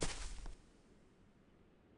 FootstepHandlerGrass1.wav